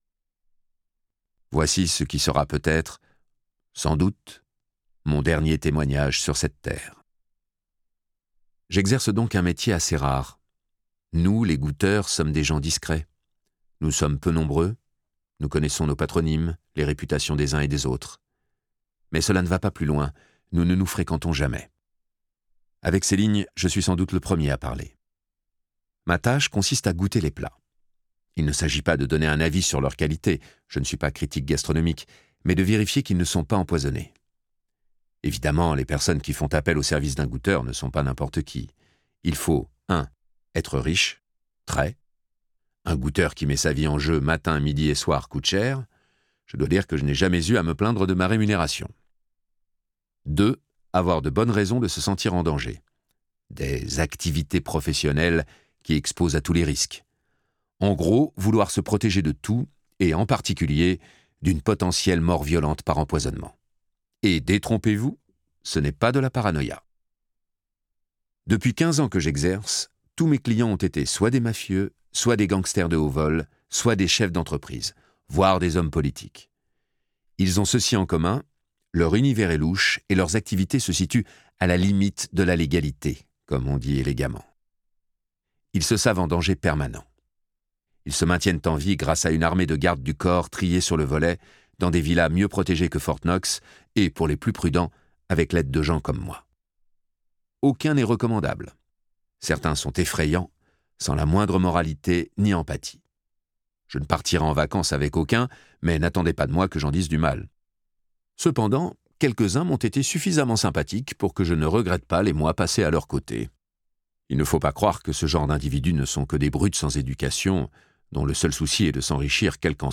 0% Extrait gratuit Le goûteur - Déguster le noir de Jacques Expert Éditeur : Lizzie Paru le : 2024-08-08 Vous n'en ferez qu'une bouchée !Un goûteur professionnel écrit : un ancien client lui a imposé, sous la menace, un marché visant à tuer l'homme qui l'emploie.